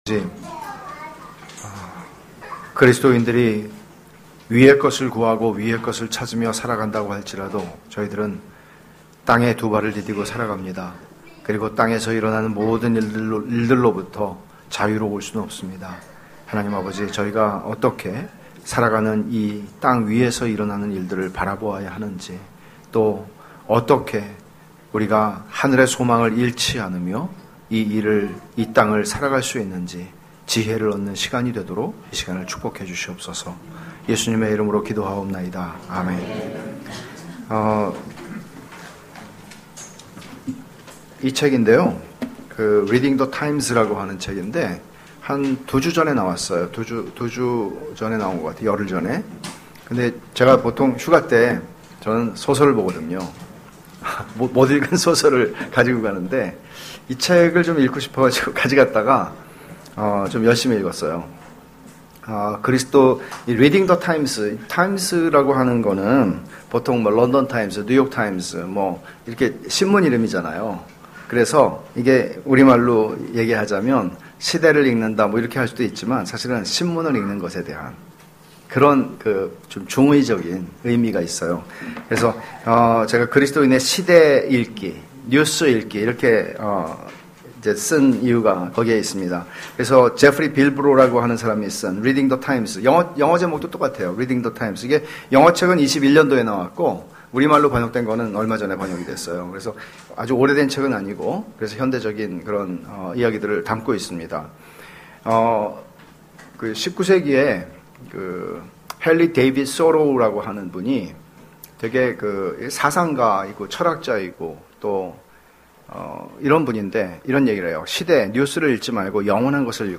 그리스도인의 시대/뉴스 읽기(음향 사고로 인해 음성/영상 일부를 삭제하였습니다.)